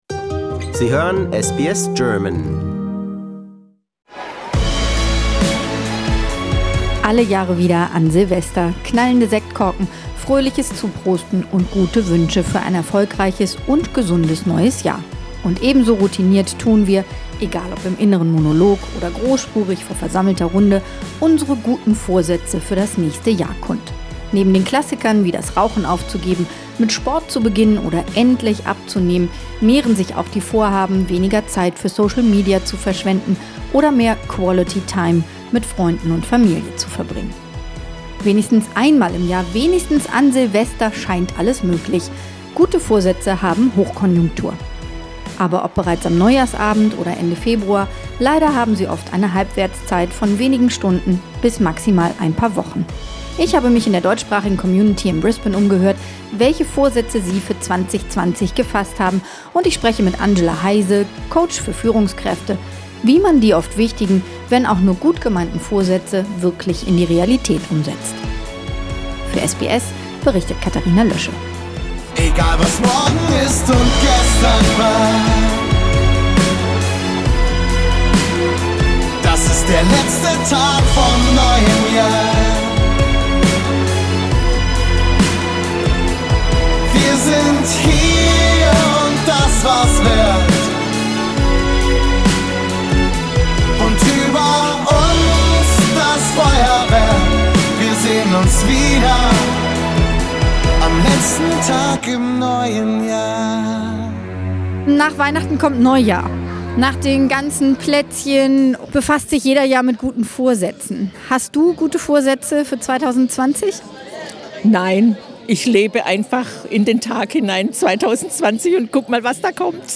hat sich in der deutschsprachigen Community in Brisbane umgehört